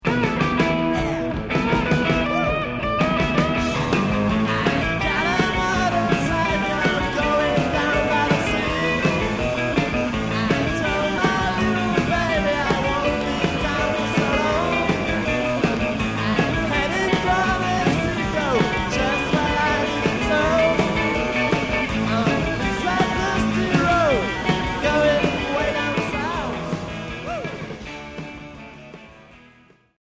Rock Premier 45t retour à l'accueil